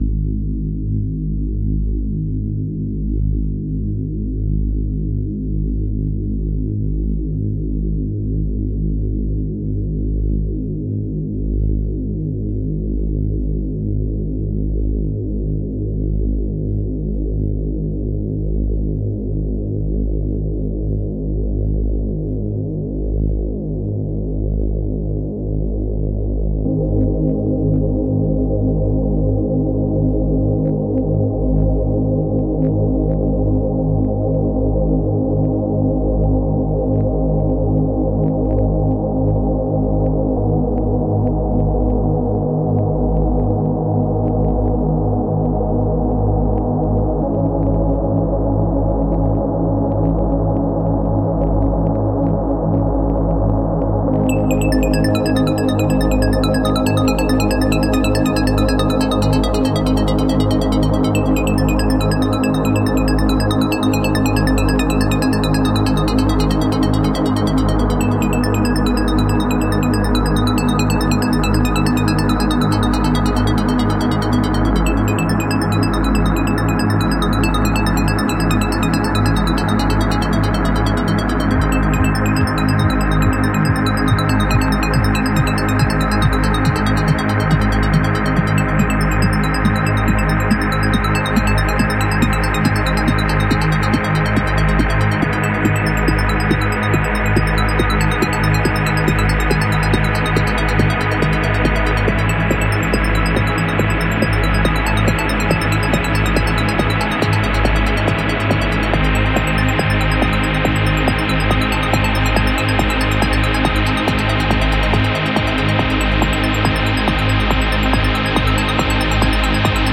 Lose yourself in the electronic.